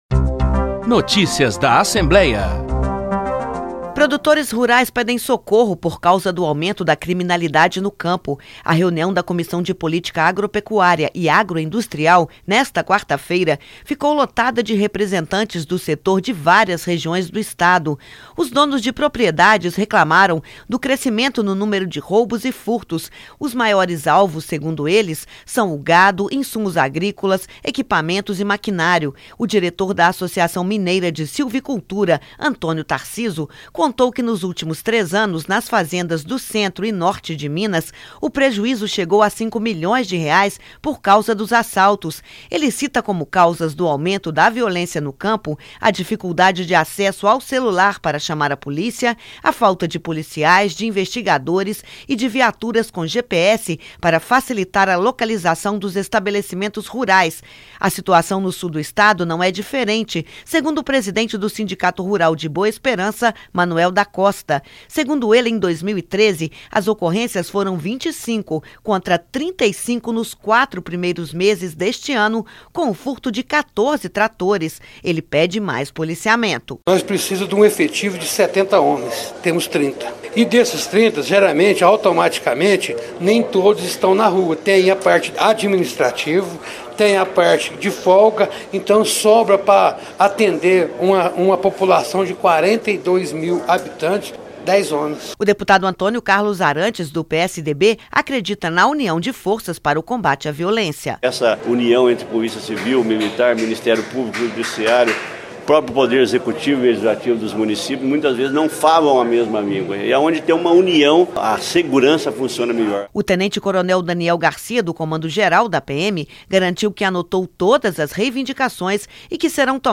Em audiência parlamentar, os fazendeiros disseram que cresceu o número de roubos e furtos, principalmente de insumos agrícolas e tratores. A PM garantiu que irá tomar medidas para melhorar o policiamento.